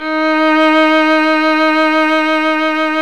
Index of /90_sSampleCDs/Roland - String Master Series/STR_Violin 2&3vb/STR_Vln2 % + dyn